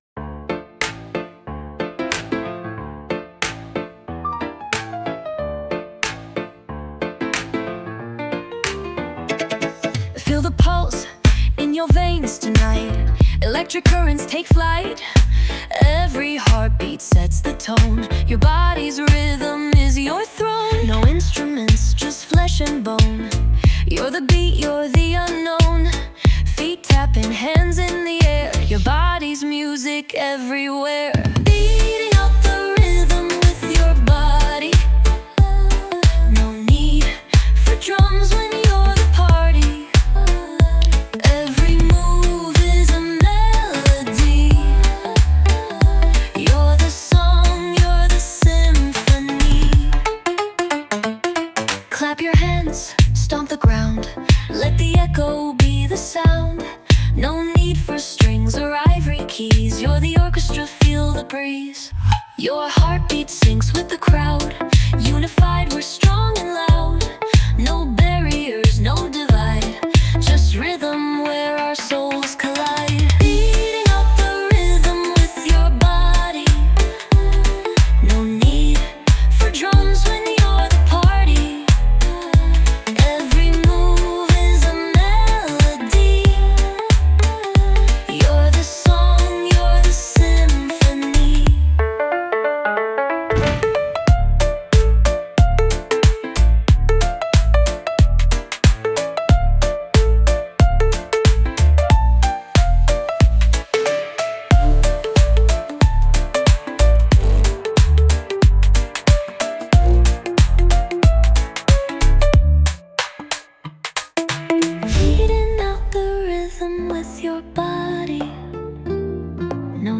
ゆったりとしたテンポに、アンニュイな女性ボーカルが乗る心地よいナンバー。